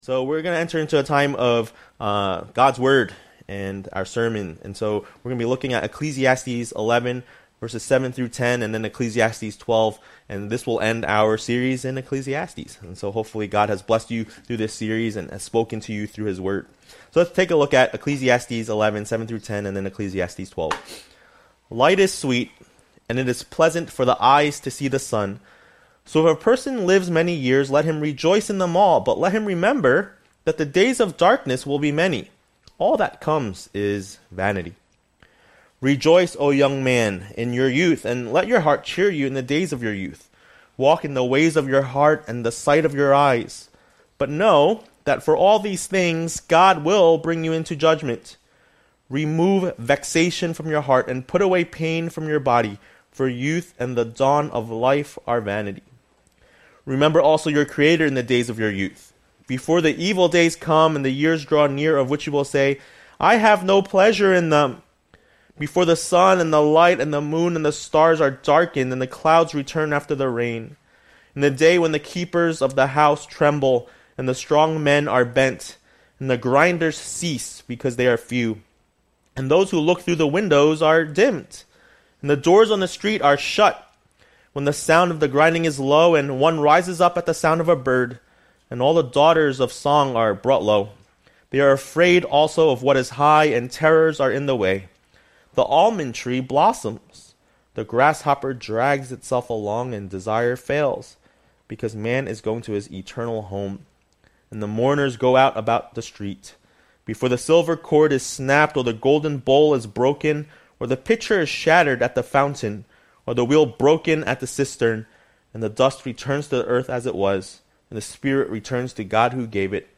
A message from the series "Ecclesiastes."